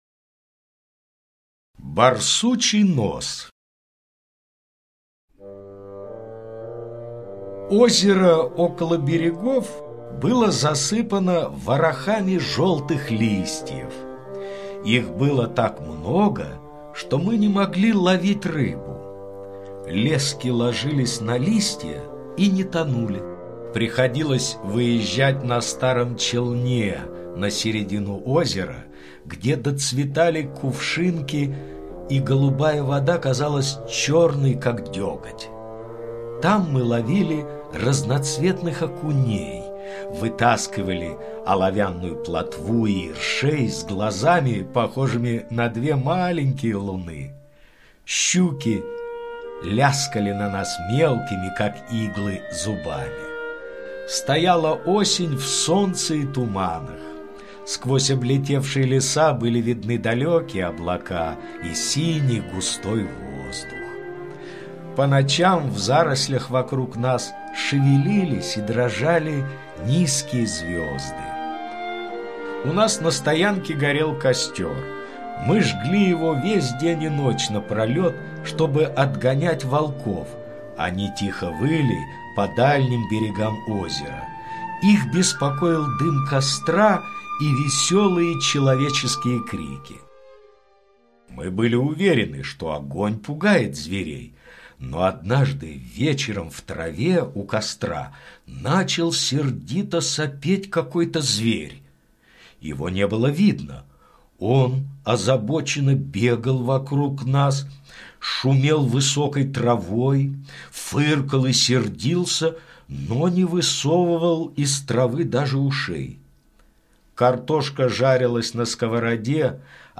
Аудиорассказ «Барсучий нос»